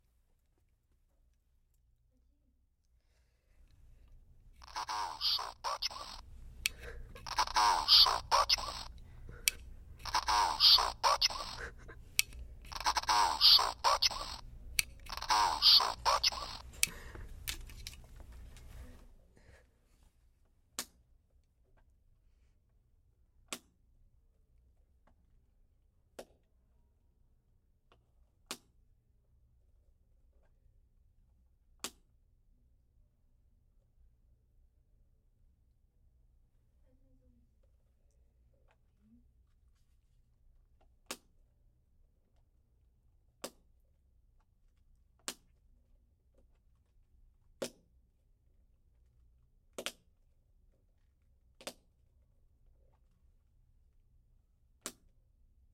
描述：由我录制的克里斯蒂安贝尔的著名台词'我是蝙蝠侠'